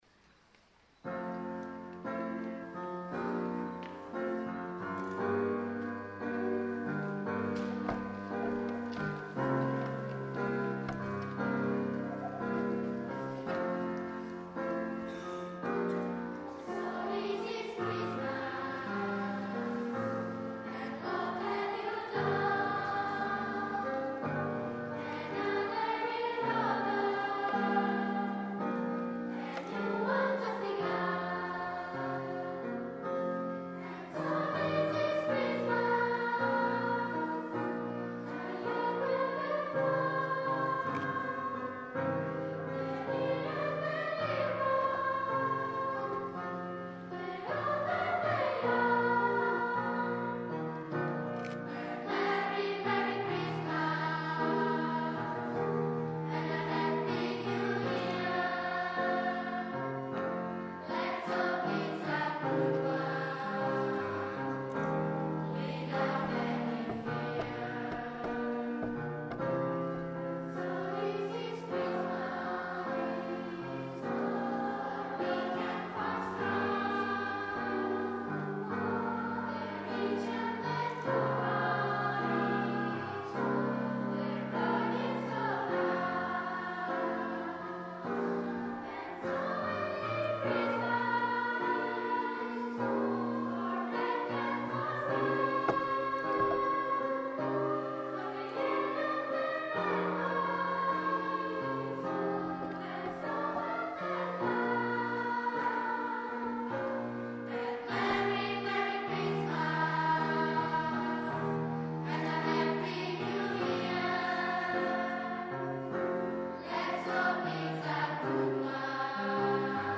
IV Rassegna corale